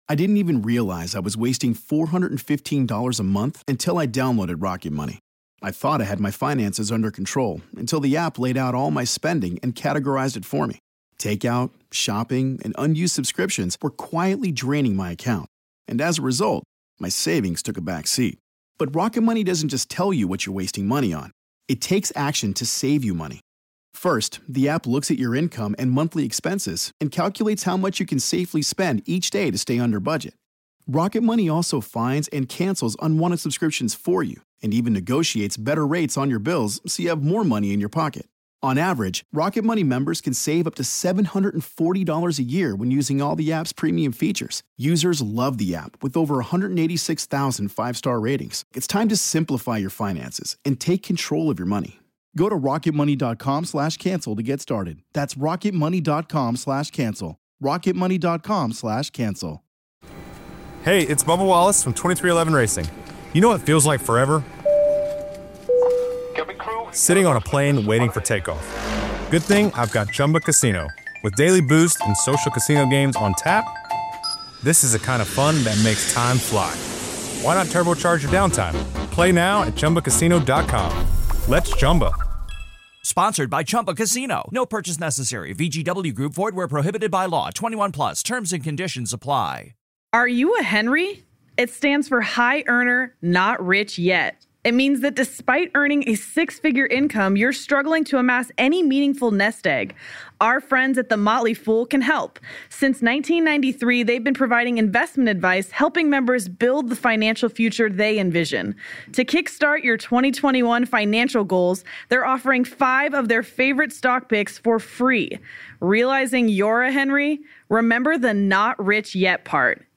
Former All-Pro fullback Lorenzo Neal joins the show to talk about the impressive play of the Raiders and 49ers, and how the Jets have given up.